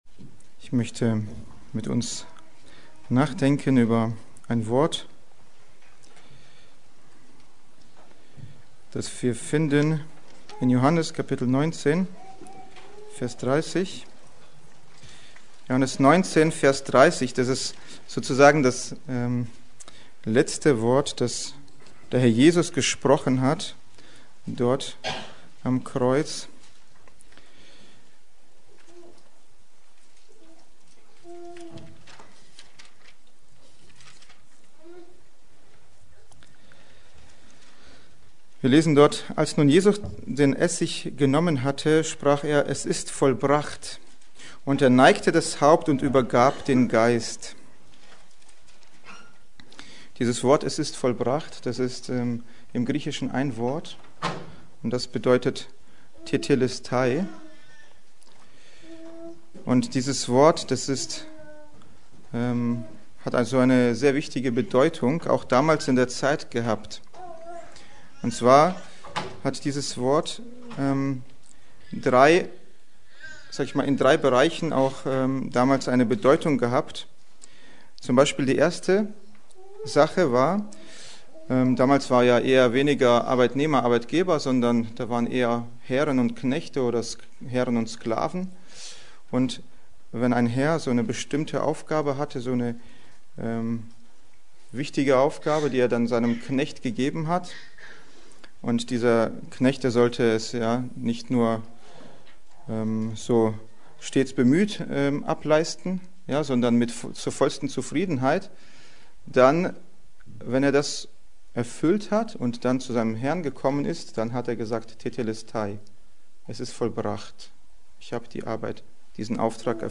Predigt: Karfreitag 2026